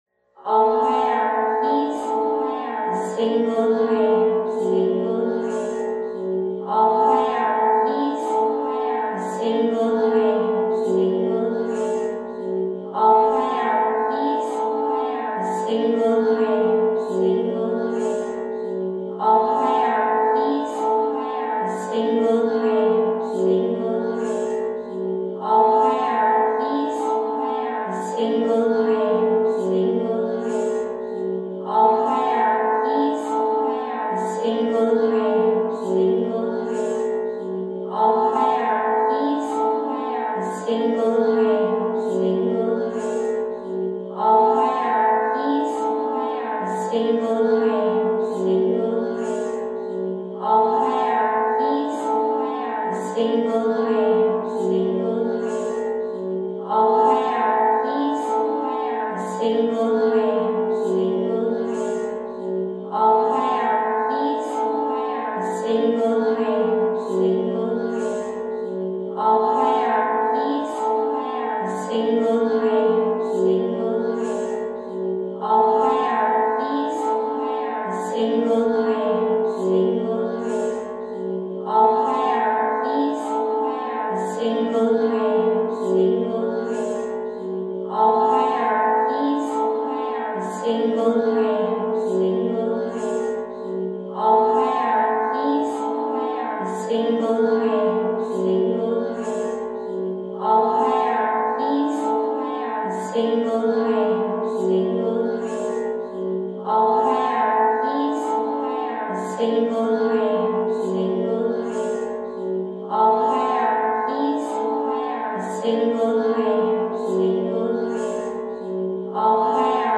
A series of chill-out-lounge moog supported chants featuring tenets of Nodism was underway when a harddrive crash lost all progress (here is a